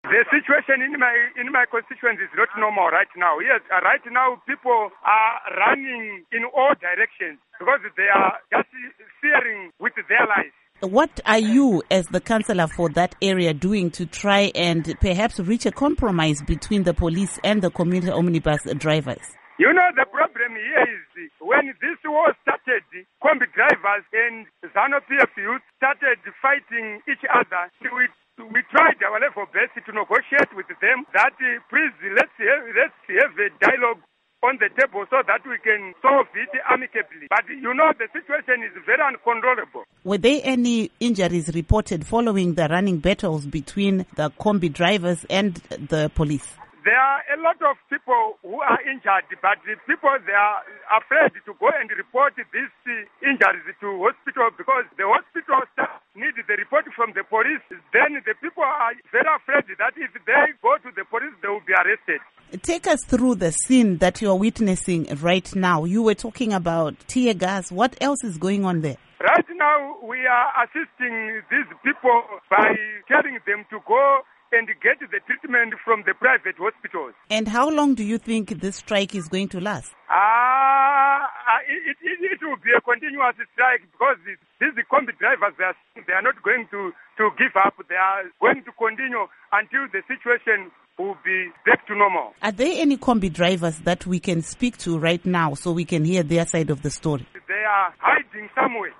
Interview With Rangarirai Mutingwende